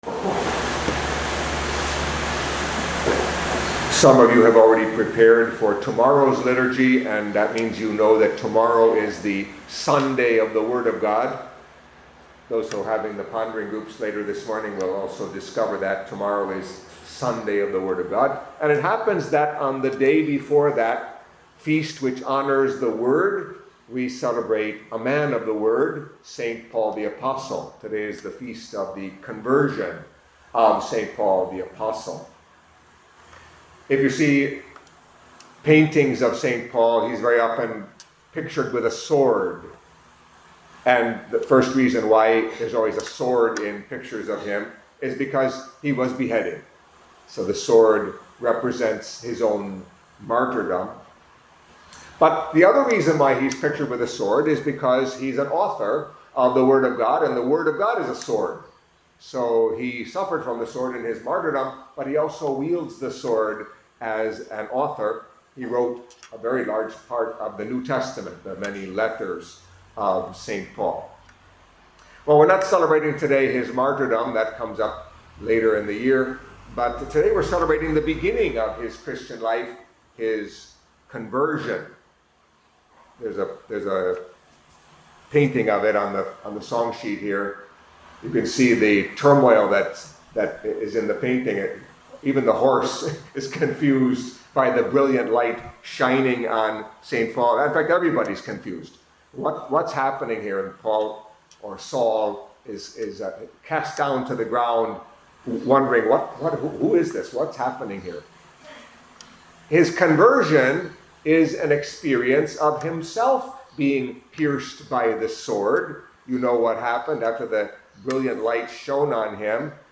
Catholic Mass homily for Feast of the Conversion of Saint Paul